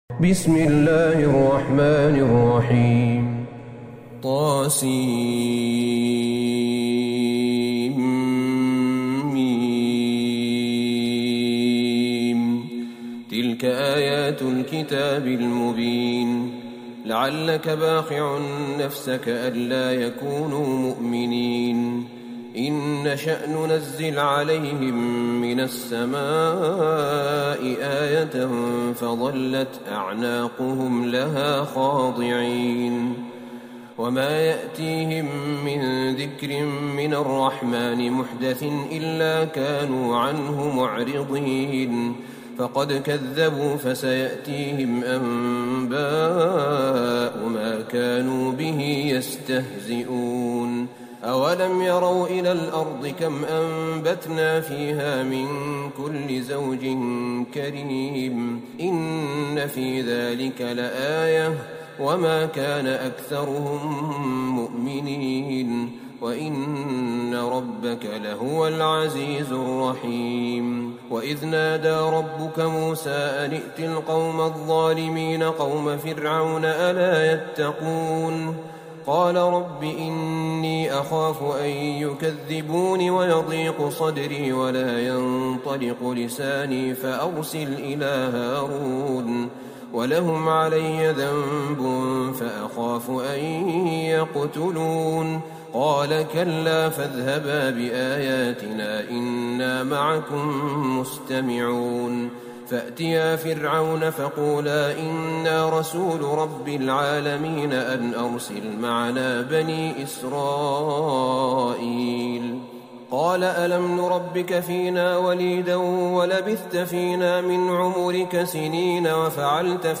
سورة الشعراء Surat AshShu'ara > مصحف الشيخ أحمد بن طالب بن حميد من الحرم النبوي > المصحف - تلاوات الحرمين